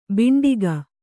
♪ bhanṭāki